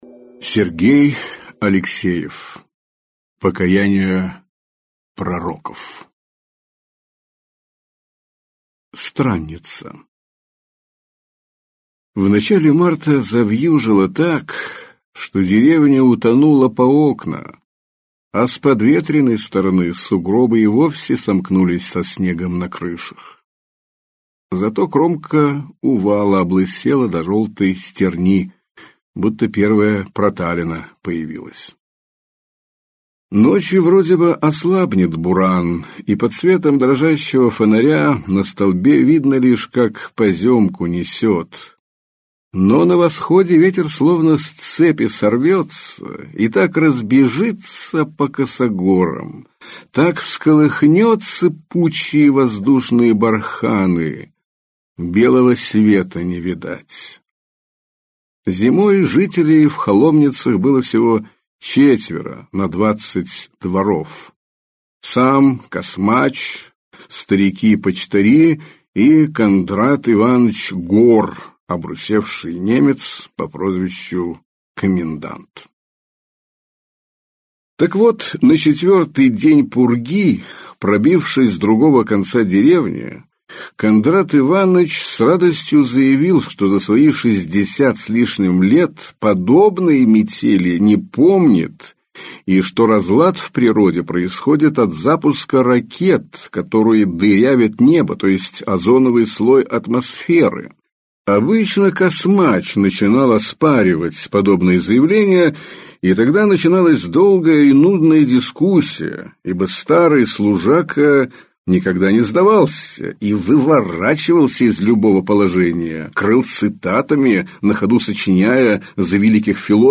Аудиокнига Покаяние пророков | Библиотека аудиокниг
Прослушать и бесплатно скачать фрагмент аудиокниги